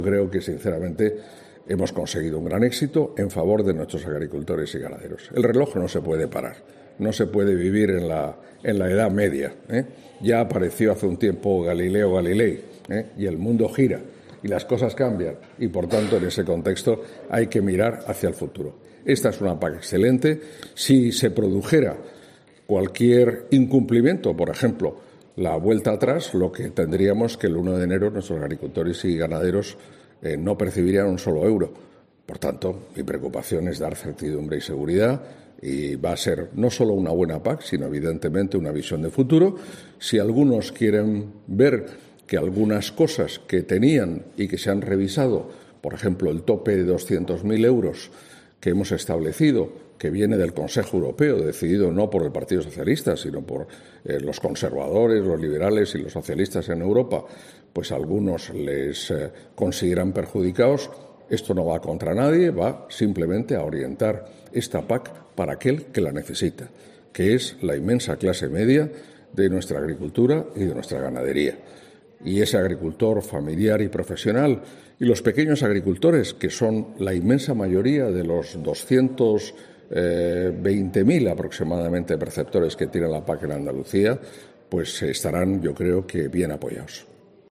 El ministro de Agricultura, Pesca y Alimentación, Luis Planas, ha dicho este viernes en Córdoba, sobre la petición de Asaja de prorrogar la actual PAC, que "el reloj no se puede parar, no se puede vivir en la Edad Media", y "ya apareció hace un tiempo Galileo Galilei y el mundo gira y las cosas cambian y, por tanto, en ese contexto hay que mirar hacia el futuro".